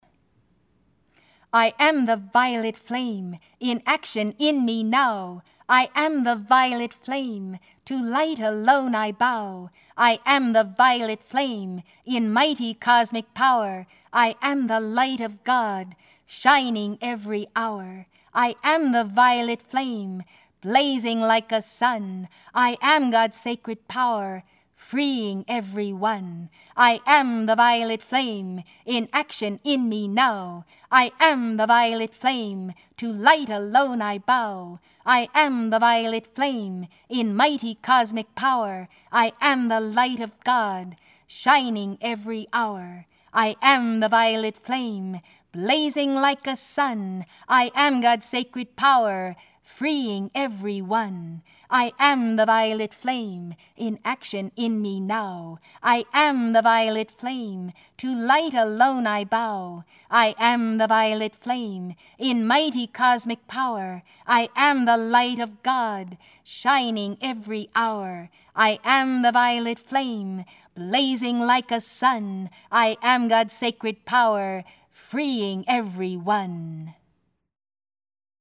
I AM the Violet Flame, Song 3x